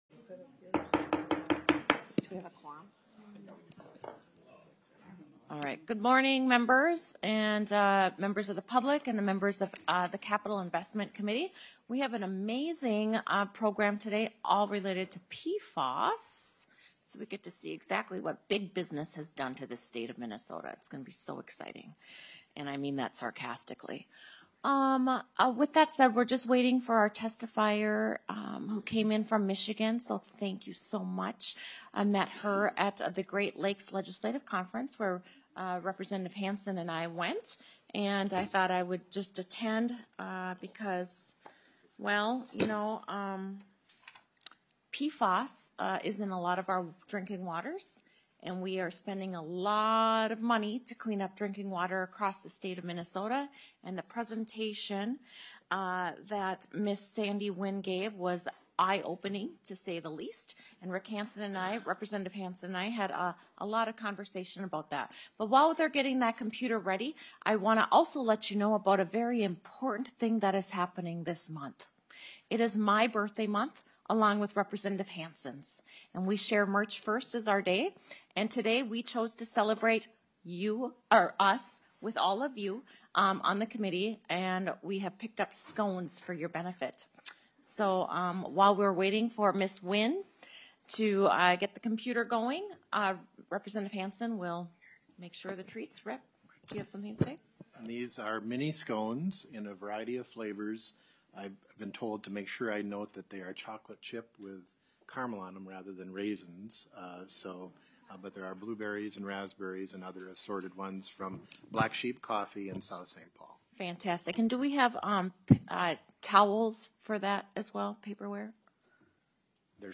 05:39 - Presentation: PFAS Chemicals Up Close and Personal.
Committee Presentation: PFAS Chemicals Up Close and Personal Presenter